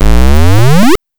ChipTune Arcade FX 04.wav